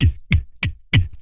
GLOTTAL.wav